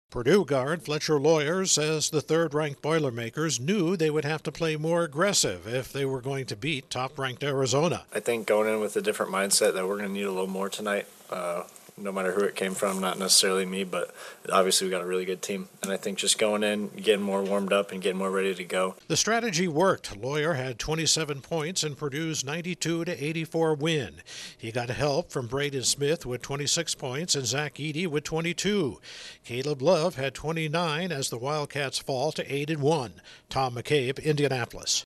Purdue wins a matchup of top-three teams. Correspondent